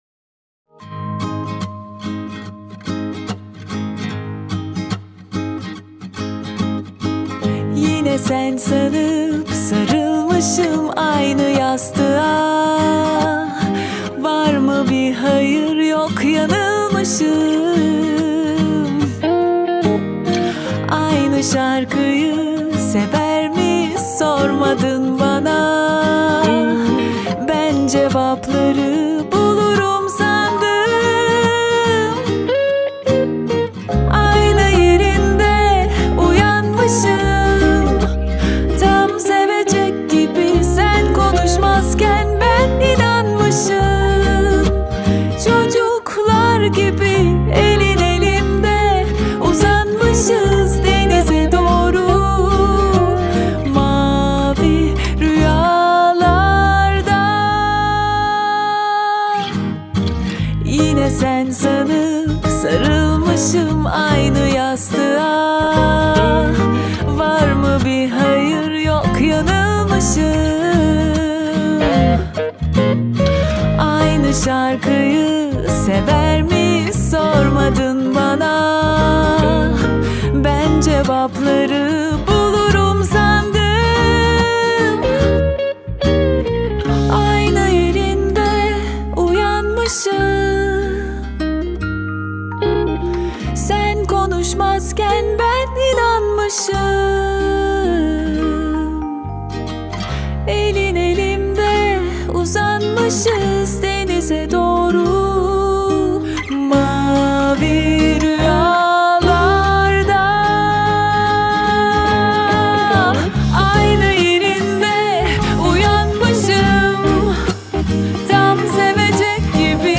mutlu huzurlu rahatlatıcı şarkı.